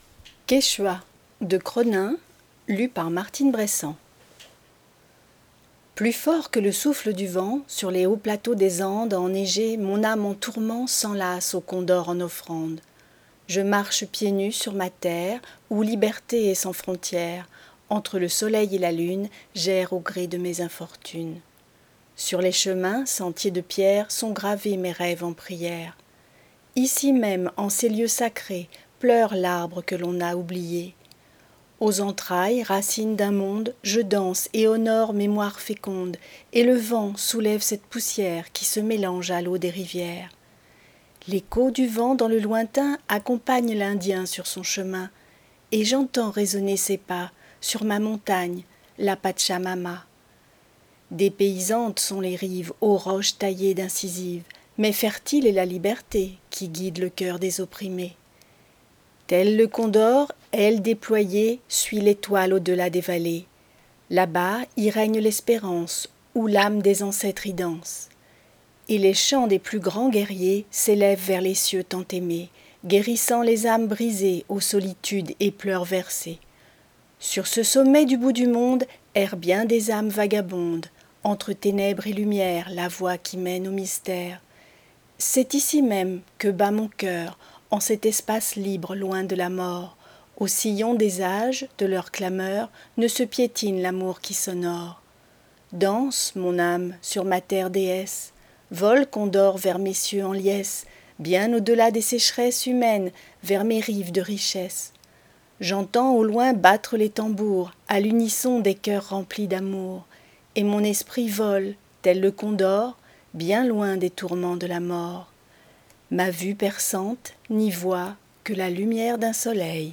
Lecture à haute voix - Quechuas
POEME